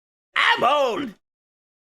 (Isolated Sound Effect) - Deltarune